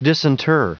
Prononciation du mot disinter en anglais (fichier audio)
Prononciation du mot : disinter